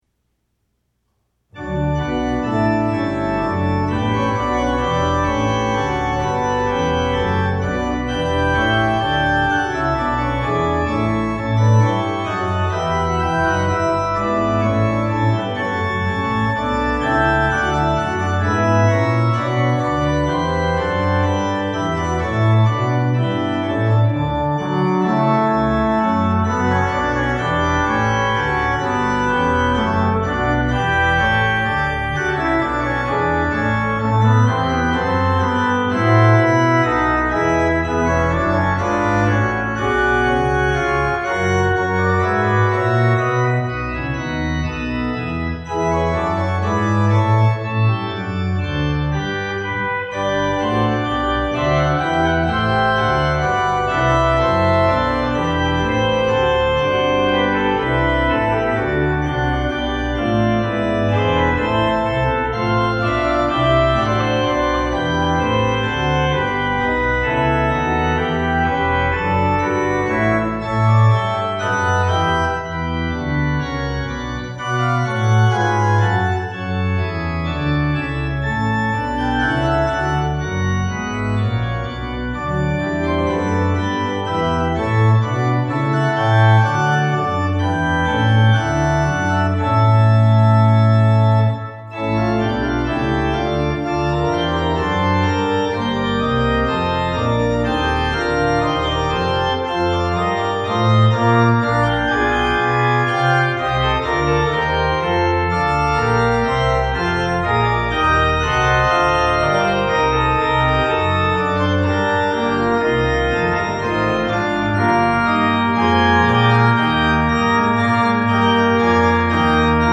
Organ  (View more Intermediate Organ Music)
Classical (View more Classical Organ Music)